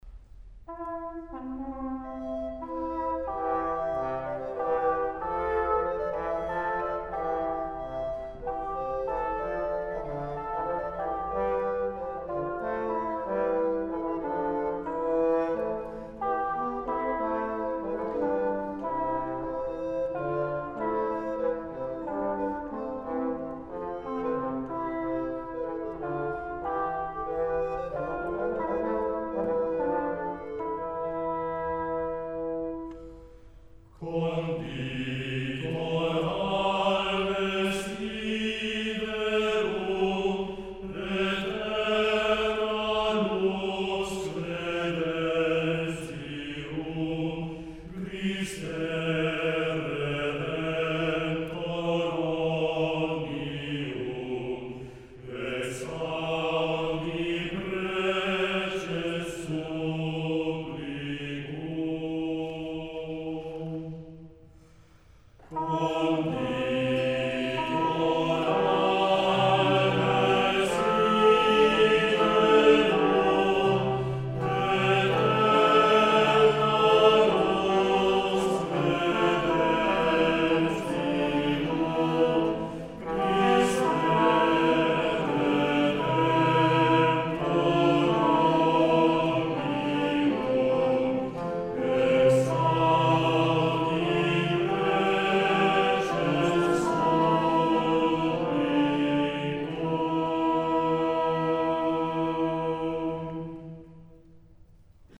MÚSICA RELIGIOSA
3 voces (Mezzo; Alto; Barítono)
Conjunto instrumental.